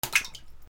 水 小さいものを水面に落とす
『ポシャン』